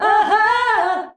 AHAAH B.wav